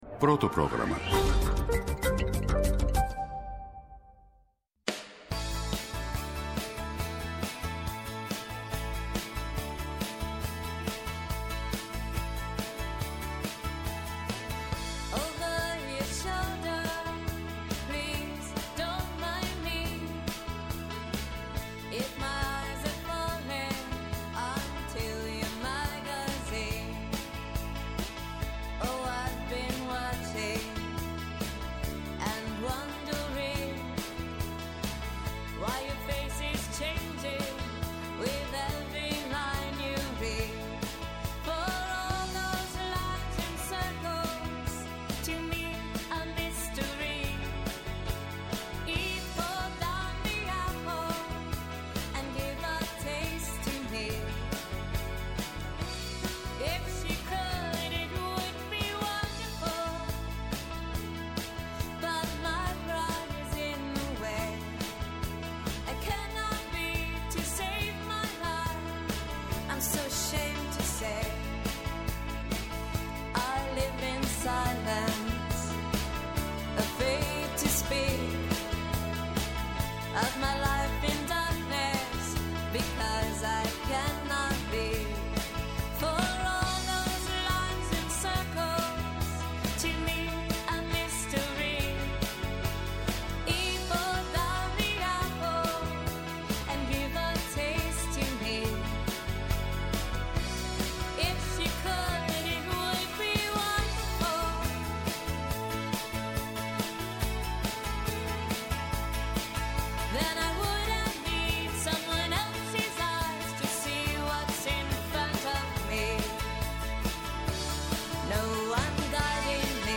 Άνθρωποι της επιστήμης, της ακαδημαϊκής κοινότητας, πολιτικοί, ευρωβουλευτές, εκπρόσωποι Μη Κυβερνητικών Οργανώσεων και της Κοινωνίας των Πολιτών, συζητούν για όλα τα τρέχοντα και διηνεκή ζητήματα που απασχολούν τη ζωή όλων μας, από την Ελλάδα και την Ευρώπη μέχρι την άκρη του κόσμου. ΕΡΤNEWS RADIO